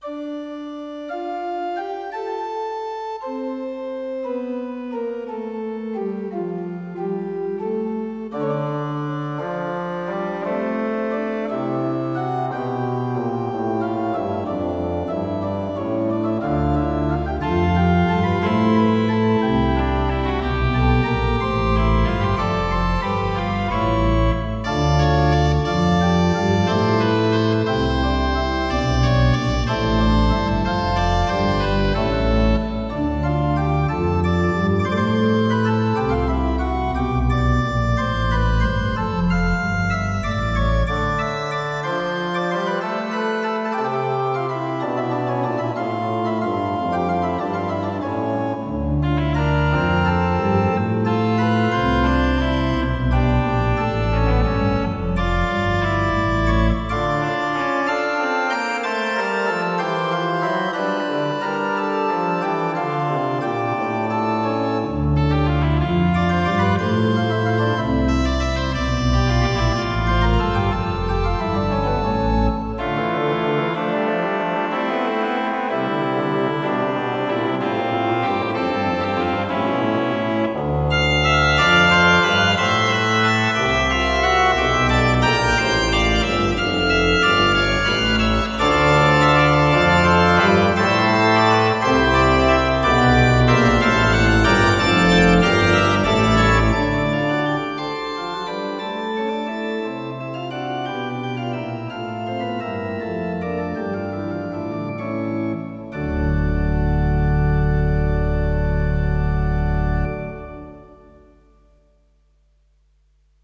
Antologia di 18 brani per Organo o Pianoforte
(per organo)